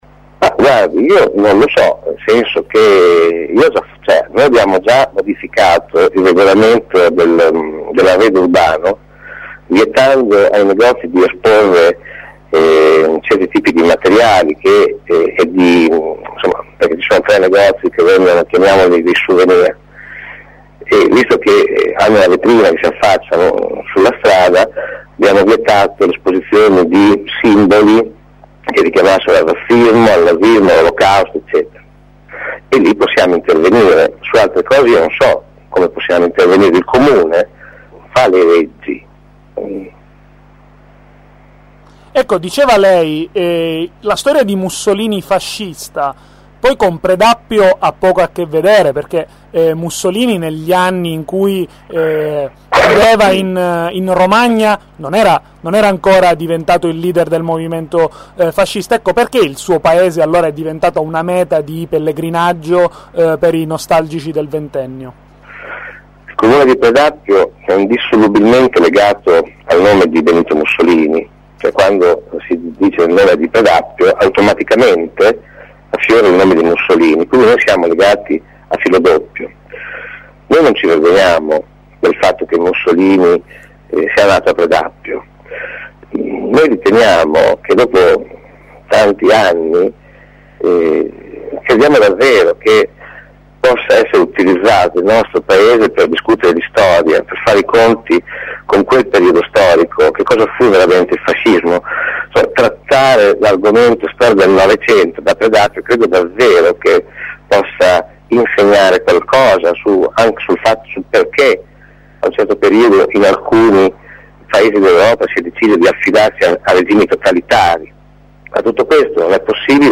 Ascolta il sindaco di Predappio